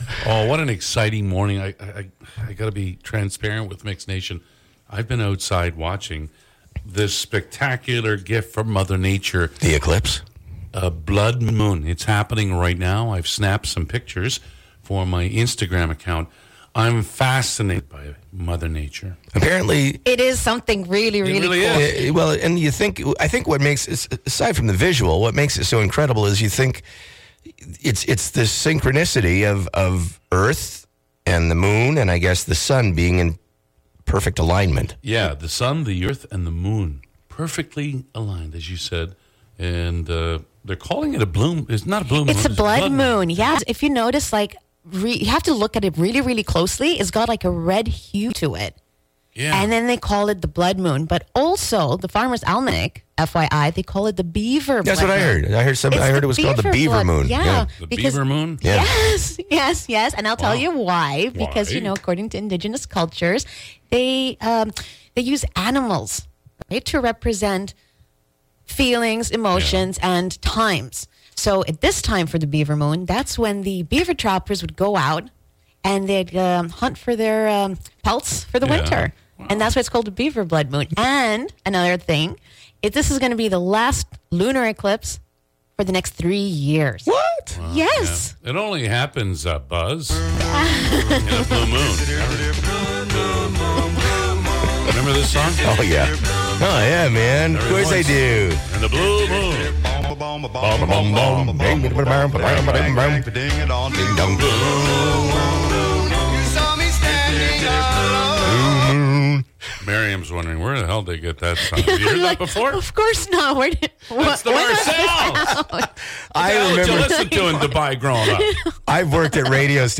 Their answers got each other in stitches laughing!